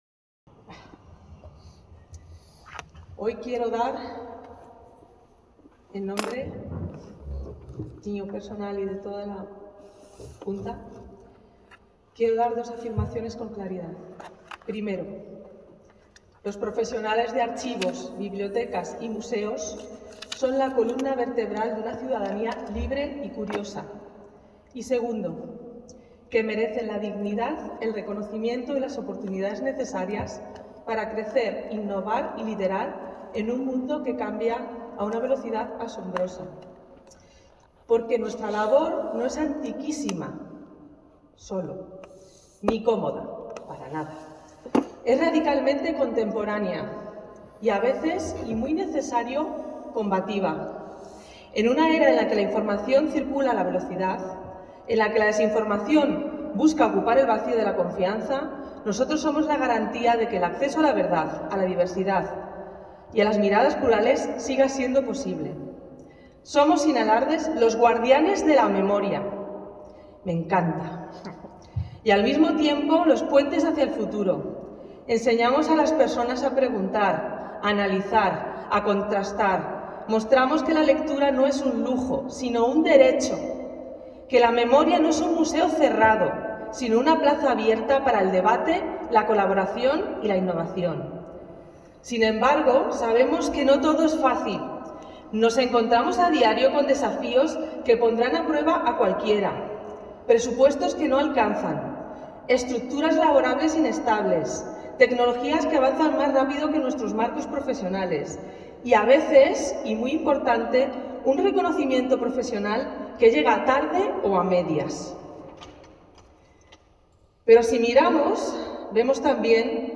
FESABID celebró su Asamblea el 11 de abril, en el Archivo Municipal de Valladolid, con elecciones a la Junta Directiva para el período 2026–2028.